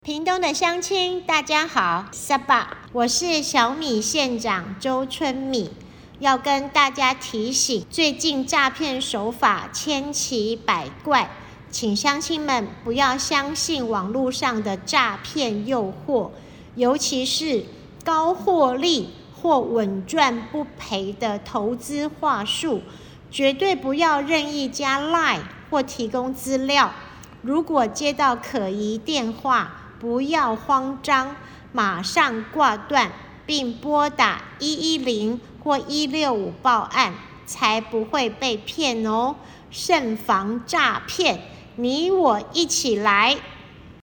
為擴大防詐宣導效益，屏東縣周縣長錄製國、臺、客家及原住民語等4種版本防詐宣導音檔